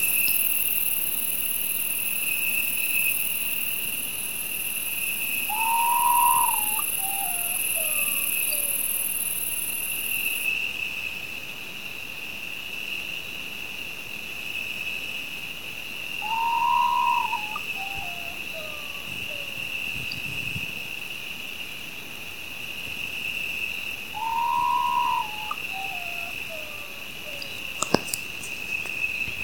Urutaú Común (Nyctibius griseus)
Nombre en inglés: Common Potoo
Provincia / Departamento: Córdoba
Condición: Silvestre
Certeza: Vocalización Grabada
urutau.mp3